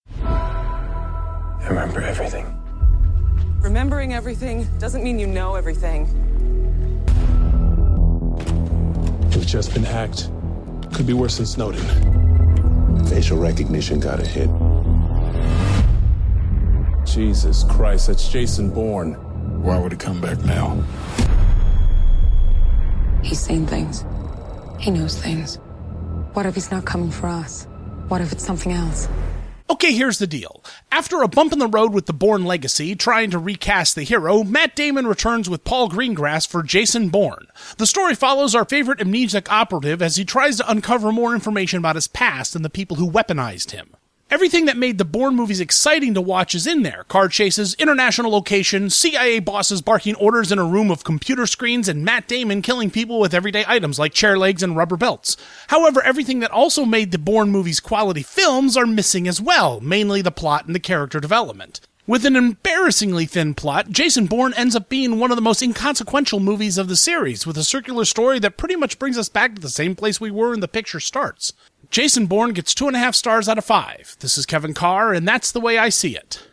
‘Jason Bourne’ Radio Review